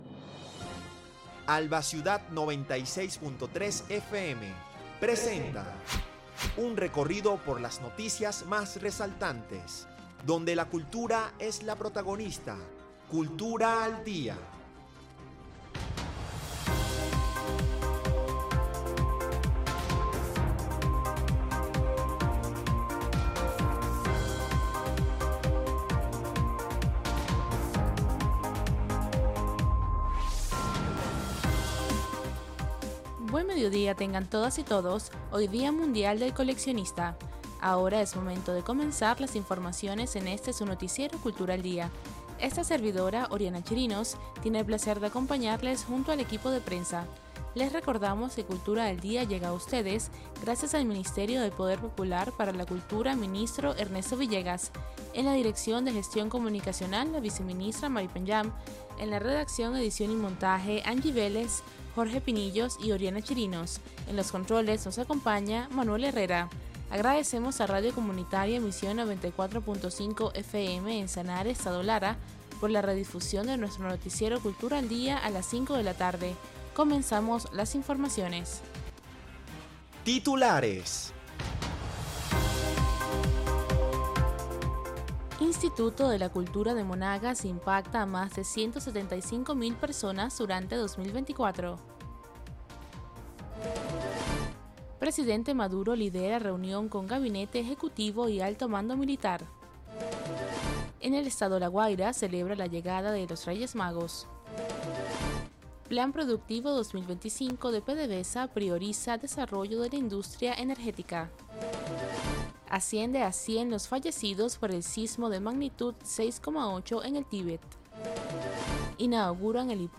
Noticiero de Alba Ciudad. Recorrido por las noticias más resaltantes del acontecer nacional e internacional, dando prioridad al ámbito cultural.